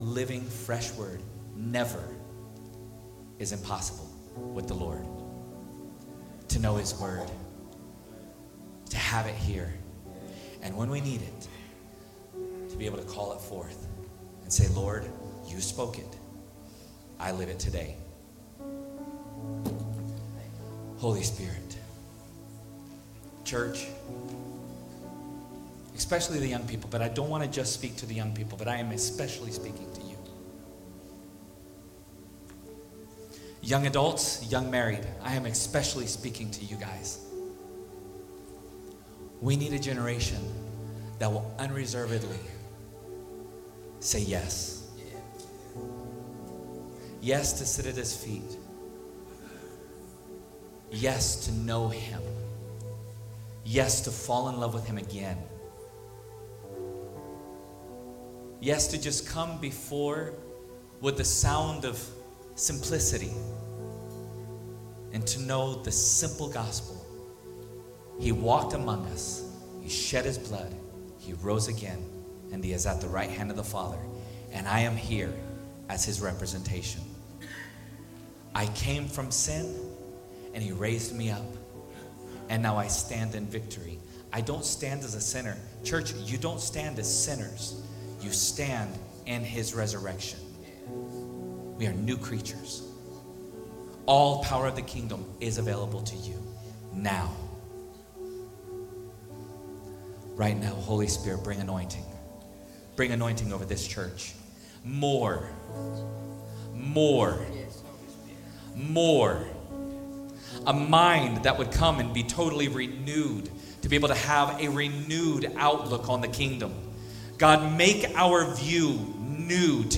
Northview Harvest Ministries LIVE